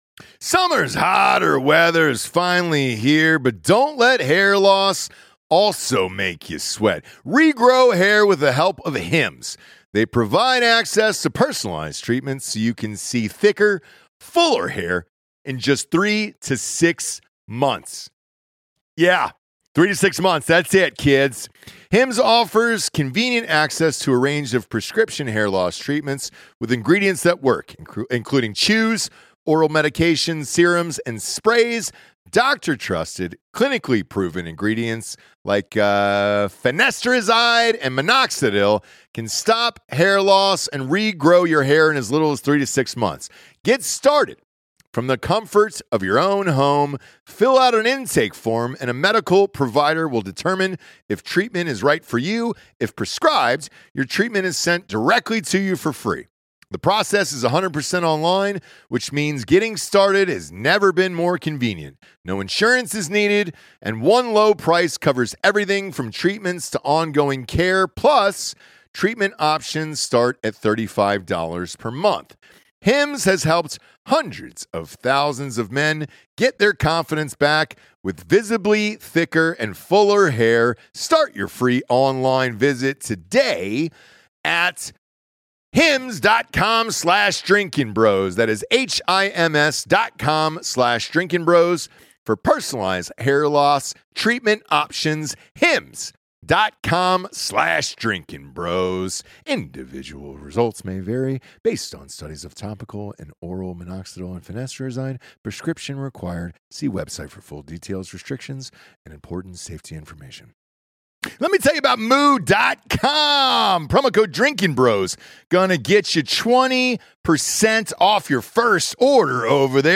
Episode 820 - Live From Dallas
The crew go live and go hard from Dallas.